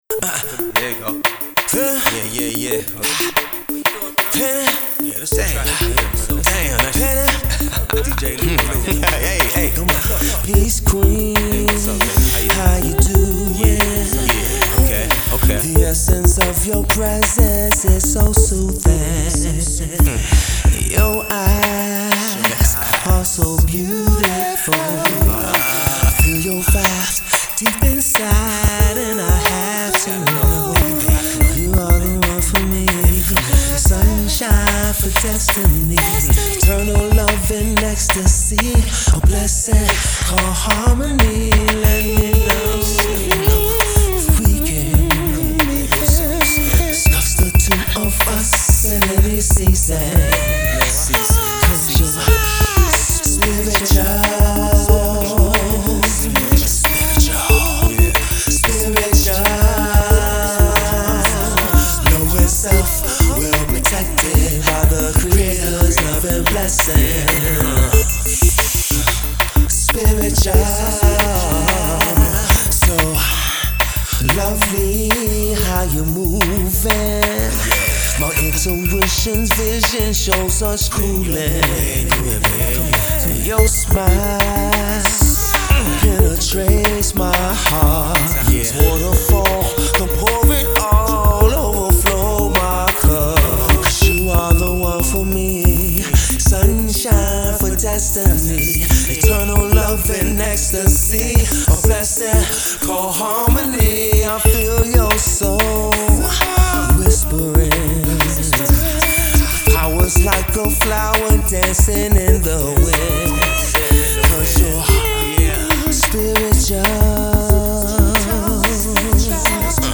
New Neo Soul R&B Heat for the ladies